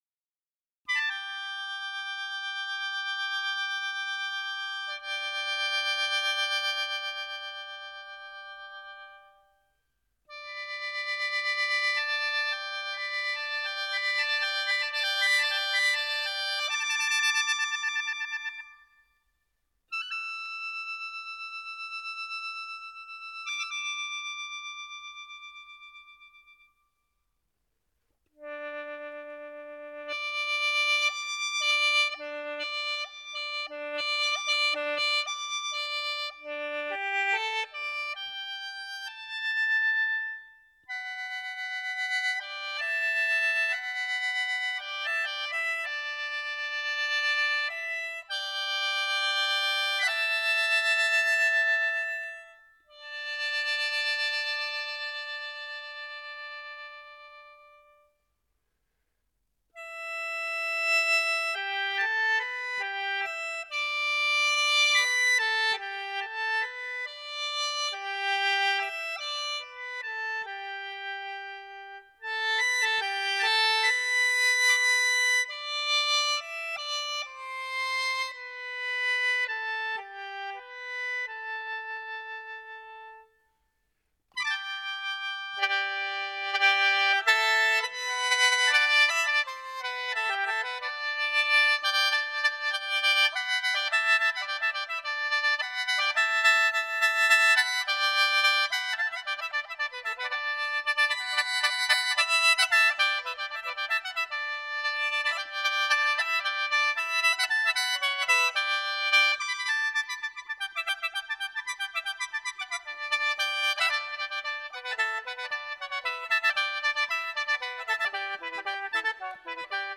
笙，是我国古老的簧管乐器，一般用十三根长短不同的竹管制成，用于吹奏。